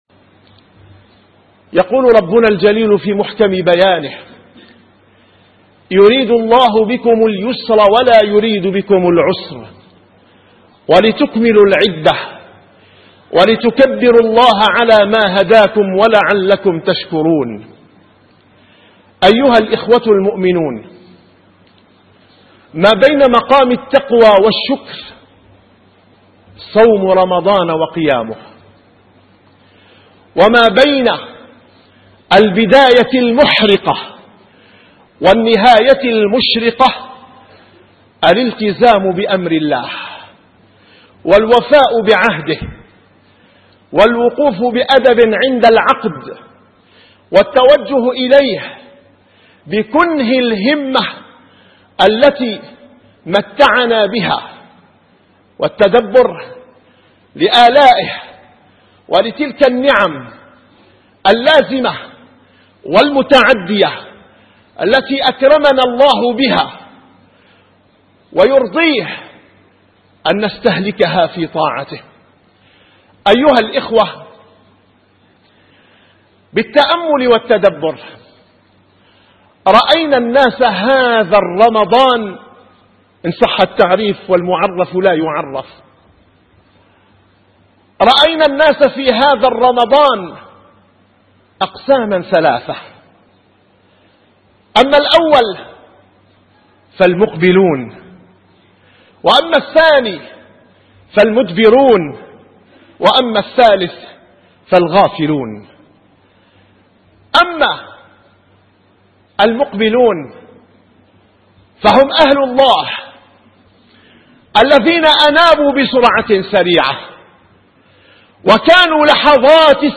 - الخطب -